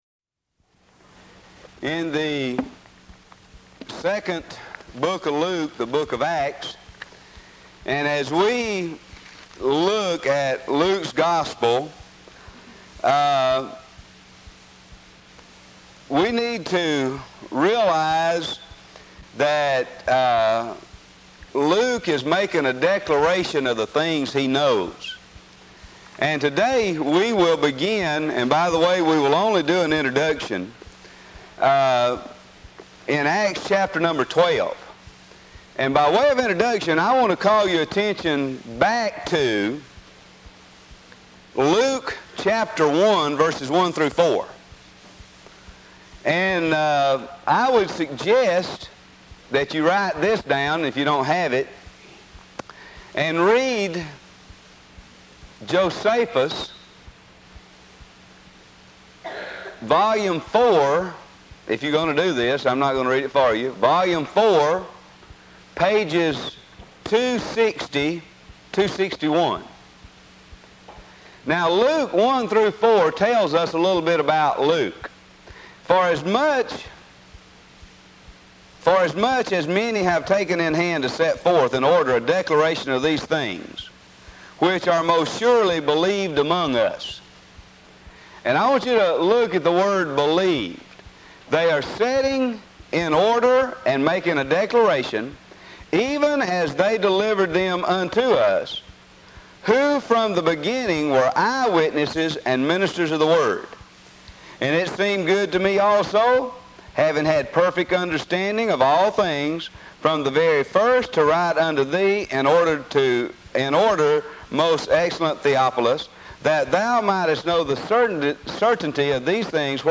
Lectures on the Judaism of Jesus and the Apostles and the Role of Non-Jews (Noahides) in this World and the World to Come